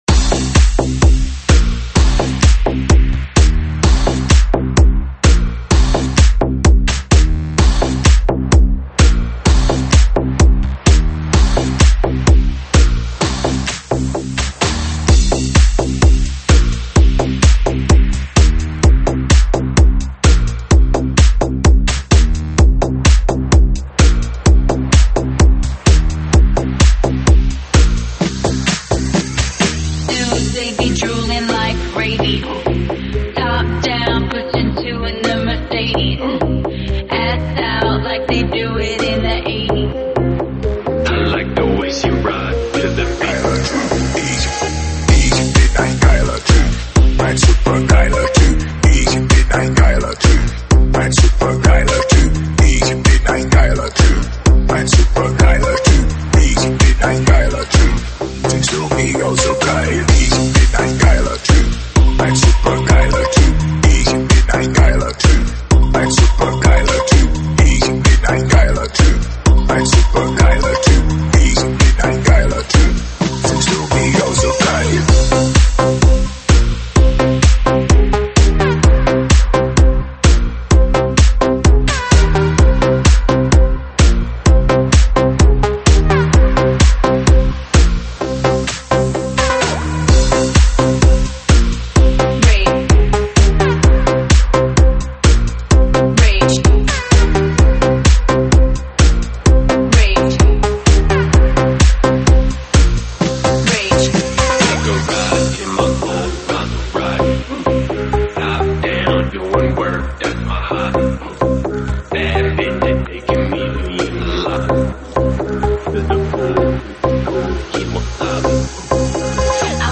现场串烧
舞曲类别：现场串烧